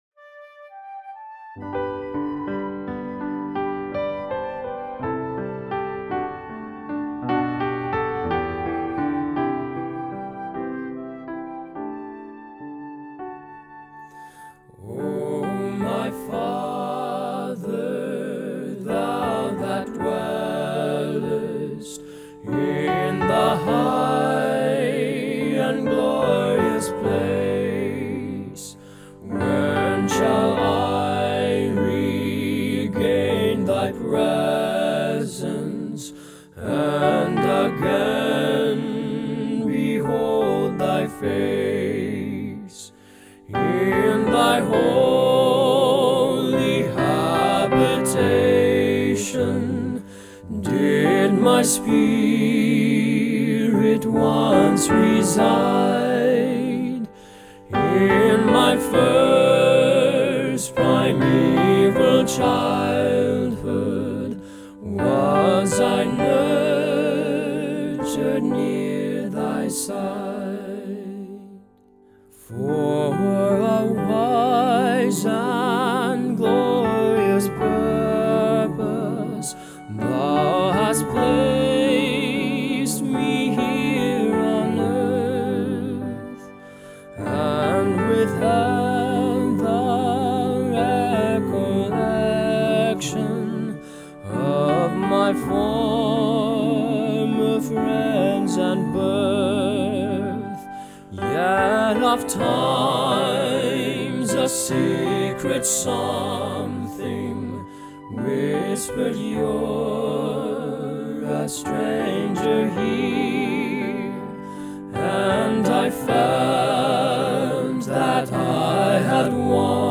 SATB, flute, and piano.
The choir sings the first three verses (quoted directly from the hymnal), with a flute entering on the second verse. The fourth verse must not be taken too quickly, especially the last line.